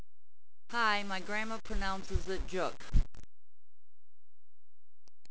Pronunciation of surname Dziuk